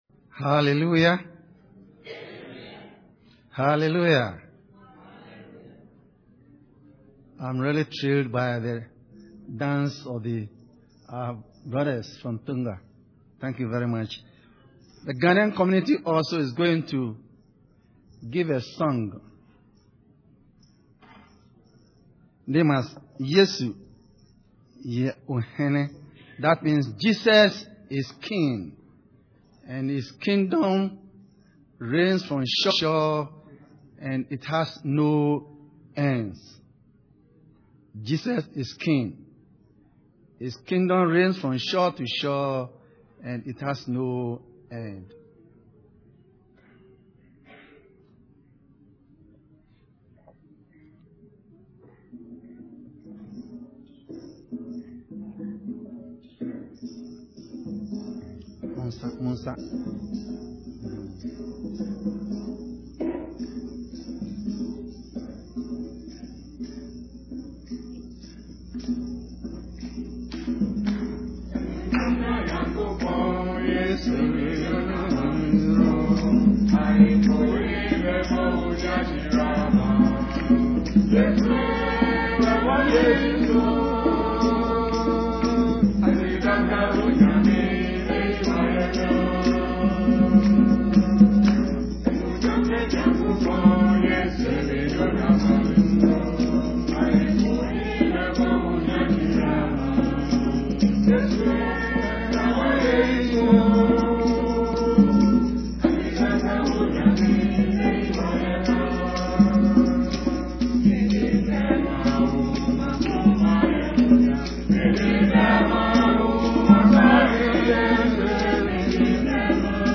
Palm Sunday Worship Service
Song
"Yesu Ye Ohene"          Ghana Wesley Fellowship Choir